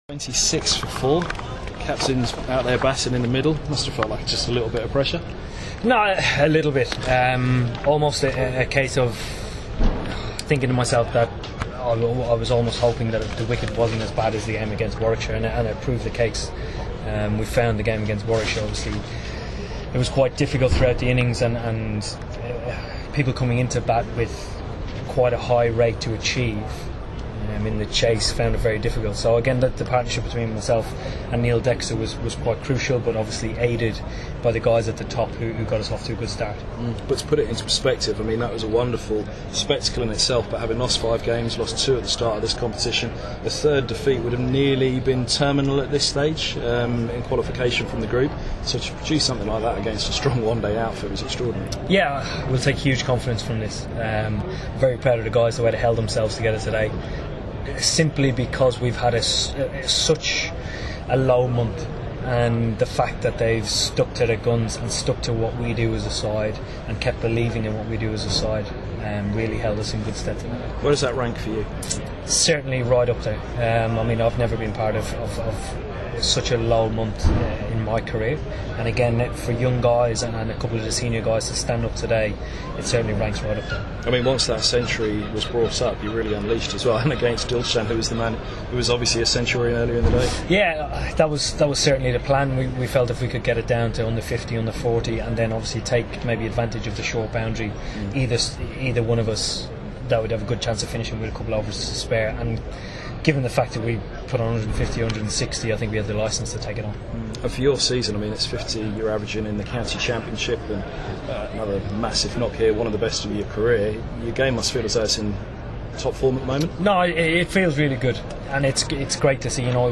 Eoin Morgan, speaking after Middlesex's Royal London Cup victory against Surrey at Lords